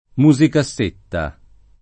[ mu @ ika SS% tta ]